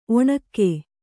♪ oṇṇakke